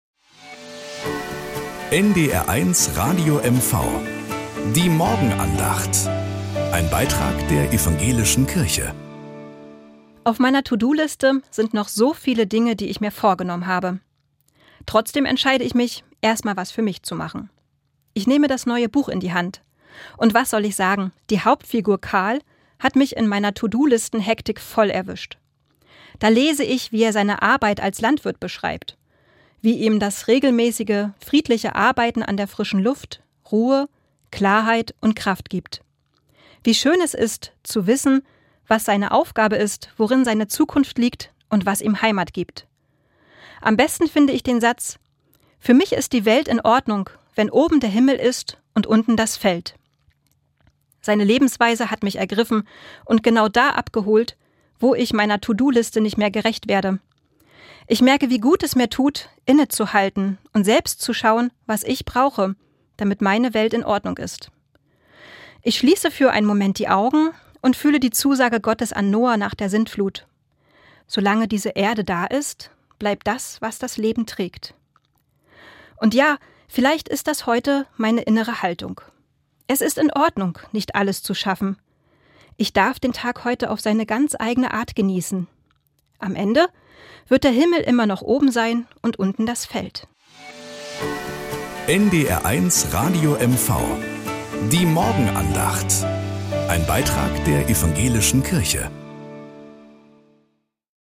Morgenandacht auf NDR 1 Radio MV